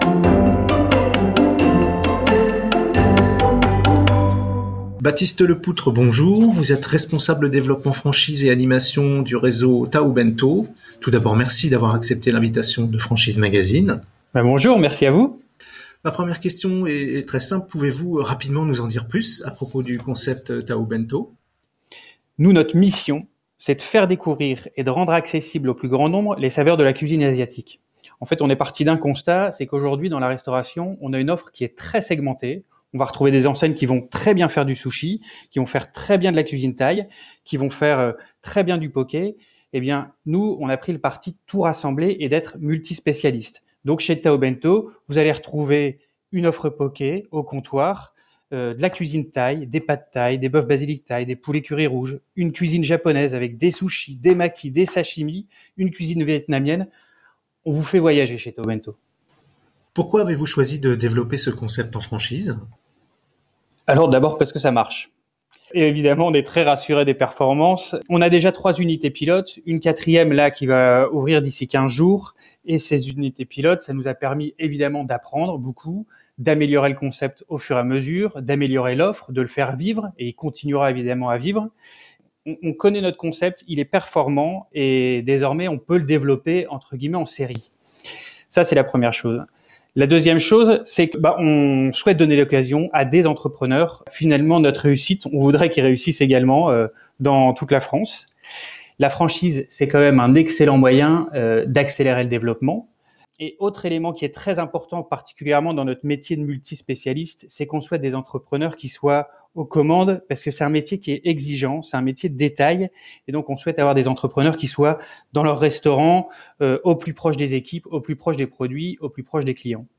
Au micro du podcast Franchise Magazine : la Franchise Tao Bento